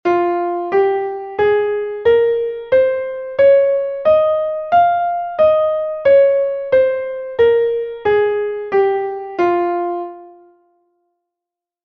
Fa+Menor (audio/mpeg)